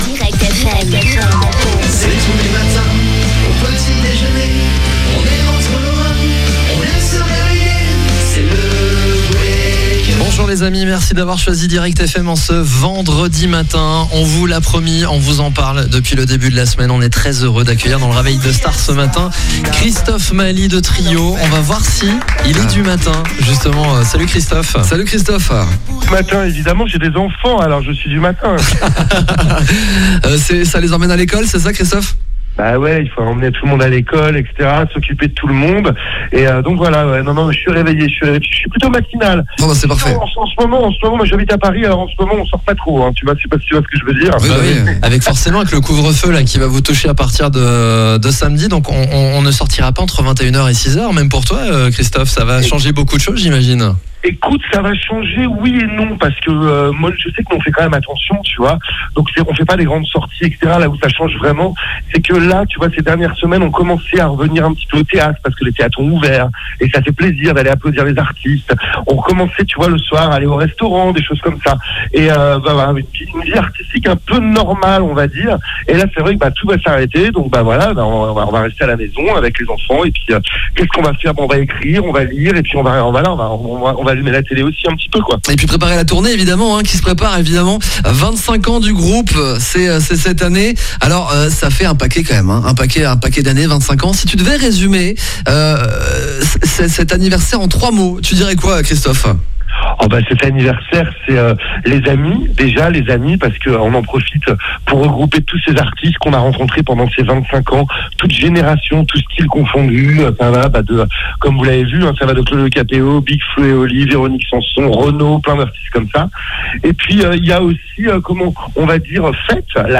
Interview-Tryo_WM.wav